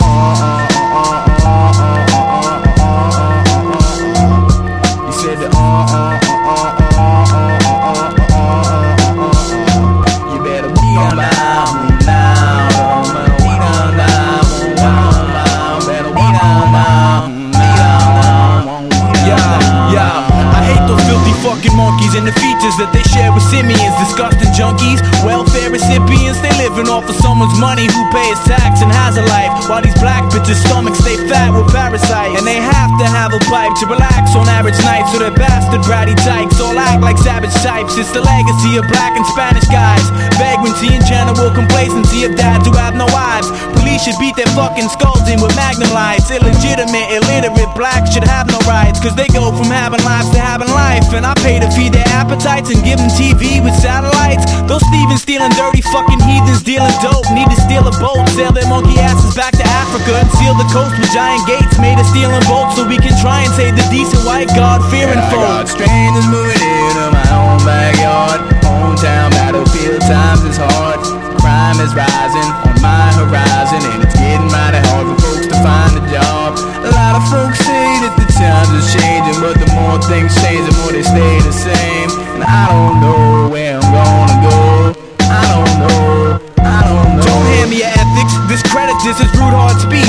メロウでポップ、インディー好きも聴いてほしいカナディアン・ヒップホップ05年作！
硬派なビート構築で魅せつつ、泣けてくるような切ないフロウが肝！